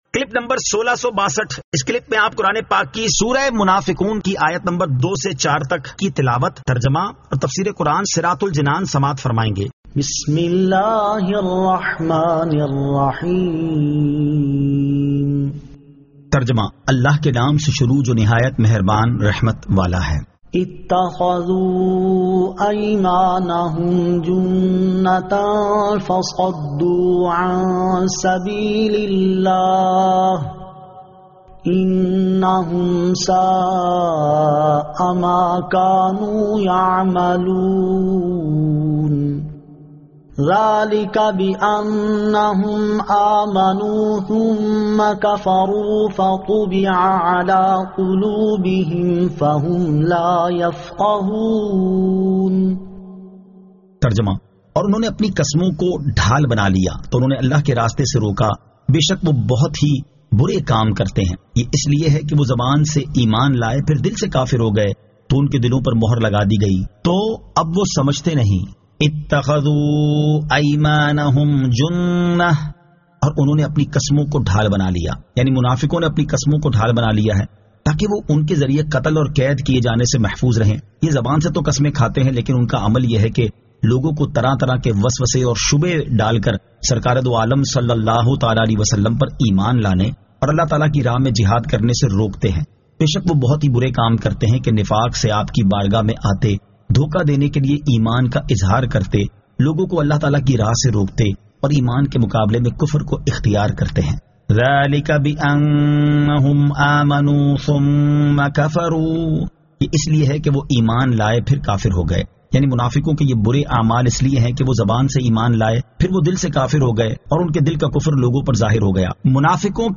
Surah Al-Munafiqun 02 To 04 Tilawat , Tarjama , Tafseer
2024 MP3 MP4 MP4 Share سُوَّرۃُ المُنَافَقُوٗن آیت 02 تا 04 تلاوت ، ترجمہ ، تفسیر ۔